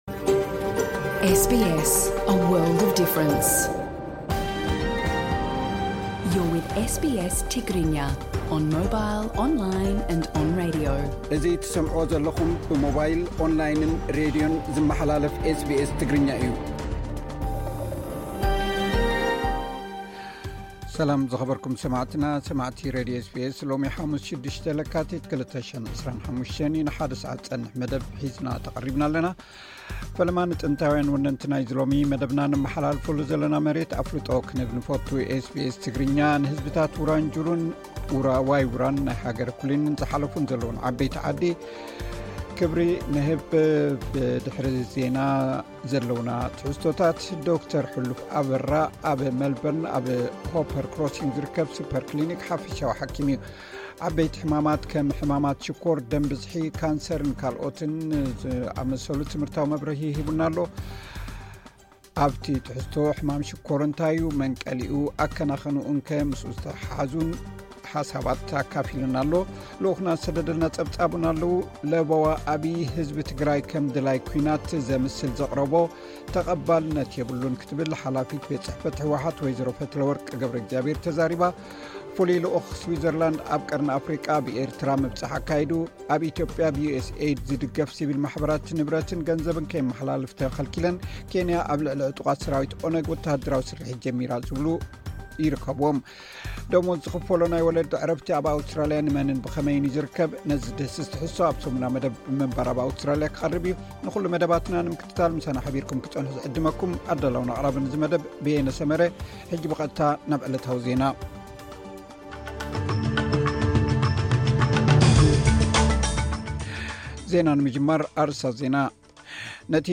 ንሎሚ ሓሙስ 6 ለካቲት 2025 ዝተዳለዉ ዜናታት።